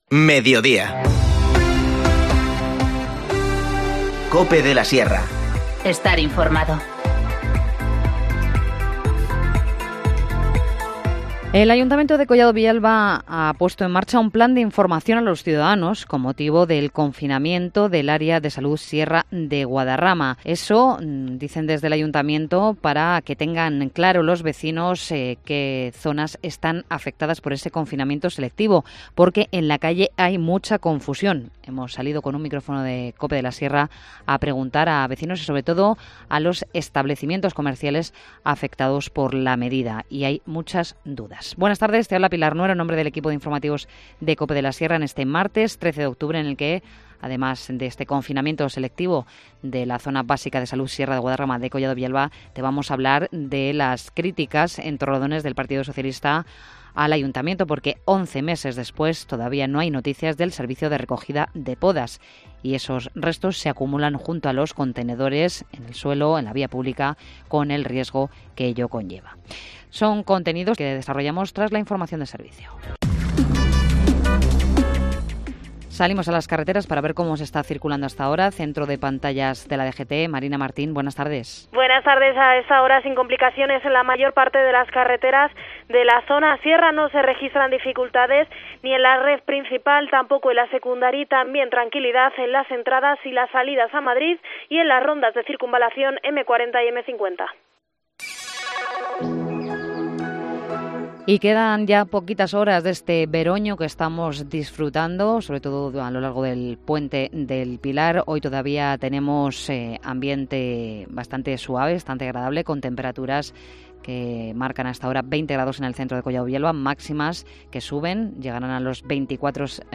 Informativo Mediodía 13 octubre